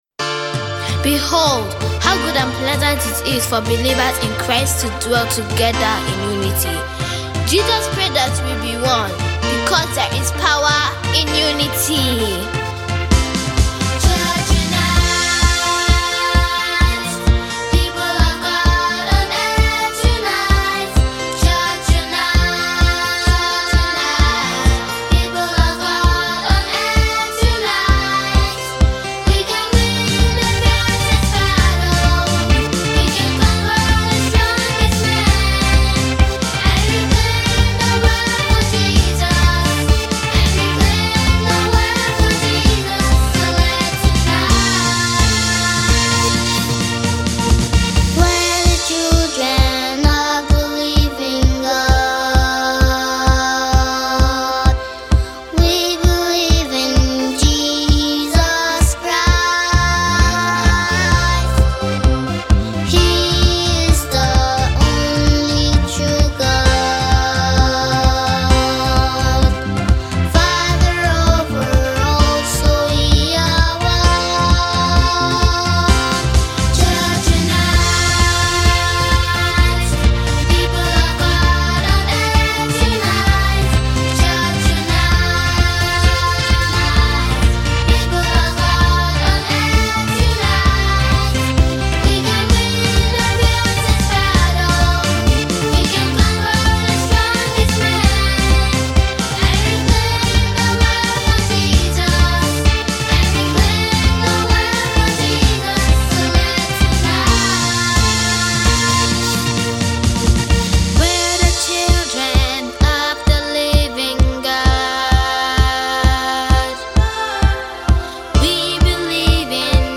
talented trio passionate singers